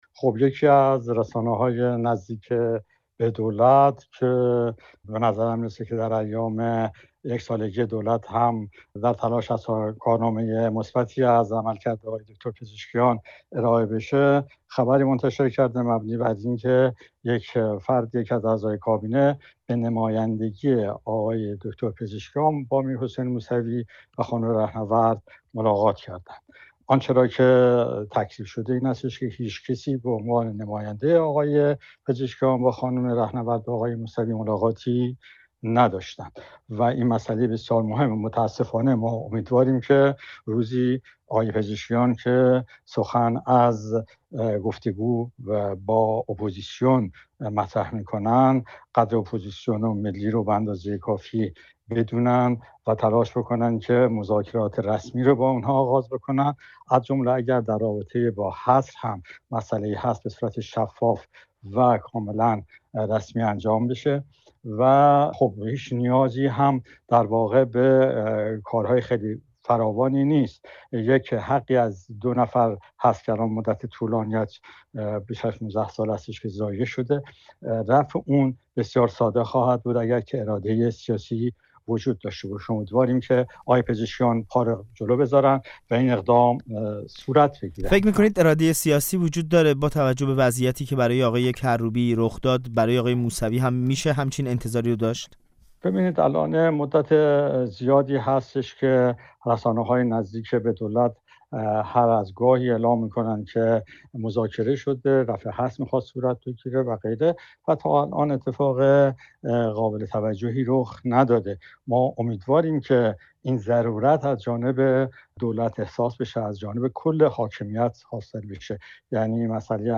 با رادیو فردا گفت‌وگو کرده است.